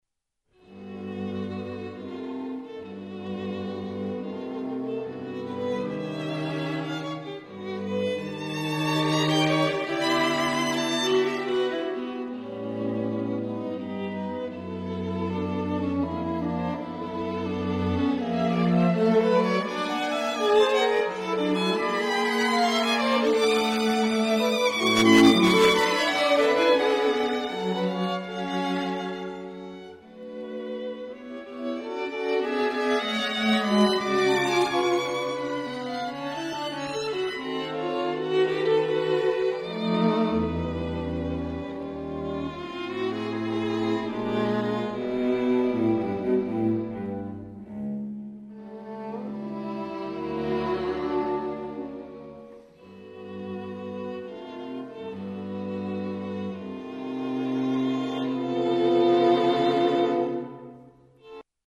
Allegro moderato (excerpts)